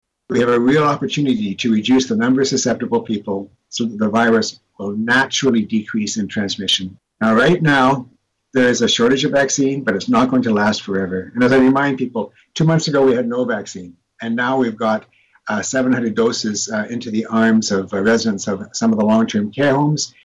During his weekly media briefing Dr. Ian Gemmill noted that the local COVID-19 cases numbers in that area have been dropping substantially and that cases of the flu nationwide are minimal as well.